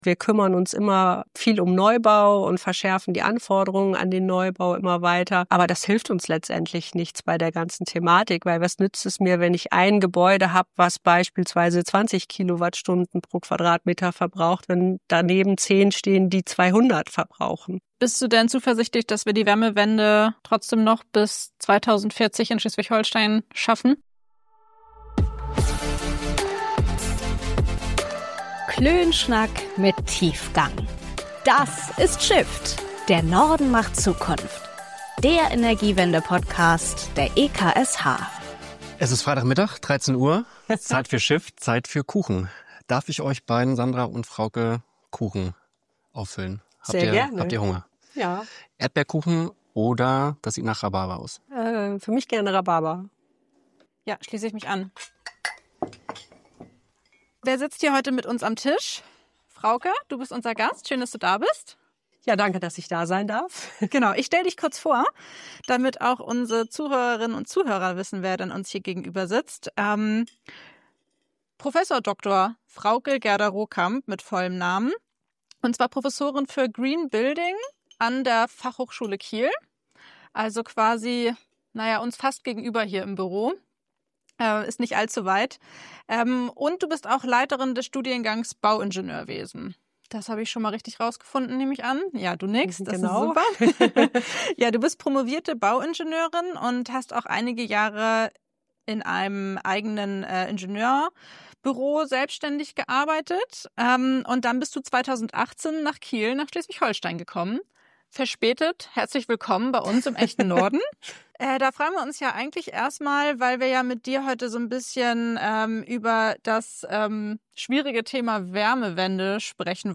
Viel Spaß bei unserem Klönschnack mit Tiefgang!